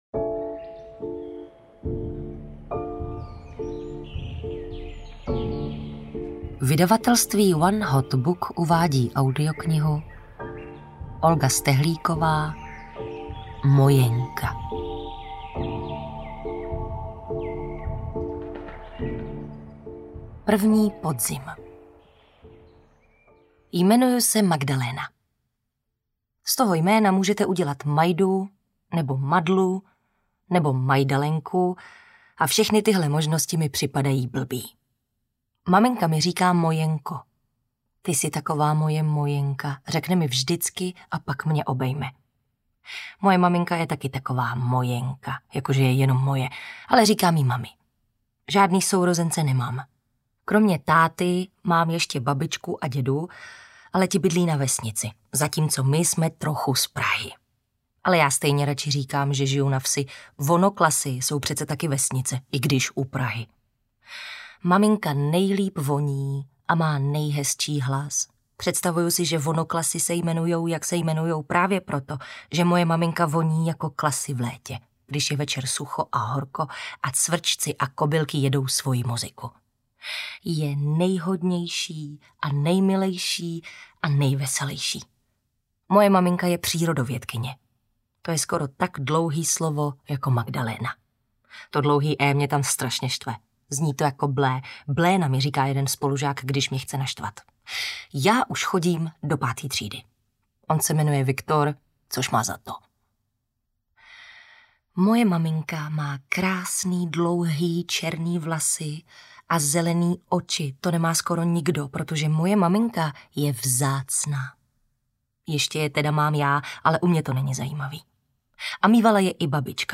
Mojenka audiokniha
Ukázka z knihy
• InterpretTereza Dočkalová